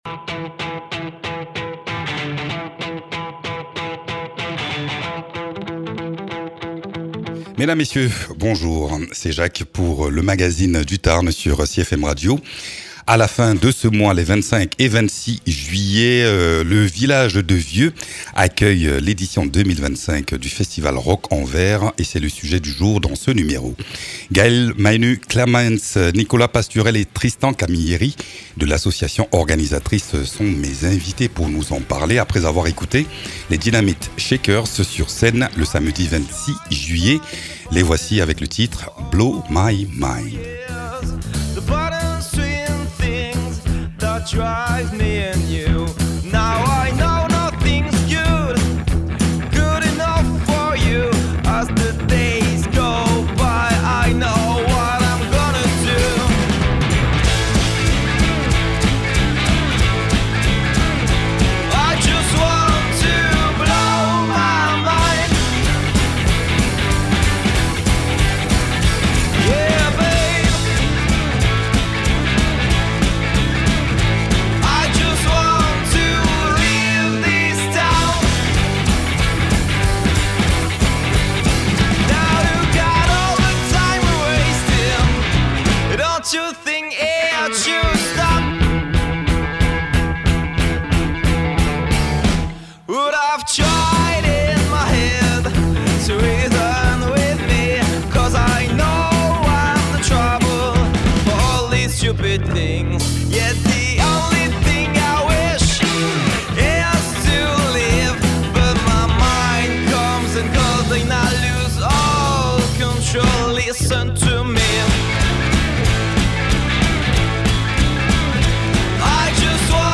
Les 25 et 26 juillet prochain, le village de Vieux dans le Tarn va vibrer au rythme du rock, du blues et de la soul ! À quelques jours du lancement du festival Rock en Vère, on tend le micro aux programmateurs passionnés de l’association organisatrice. Ils nous racontent les secrets de fabrication de cet événement qui devient incontournable dans le paysage culturel tarnais.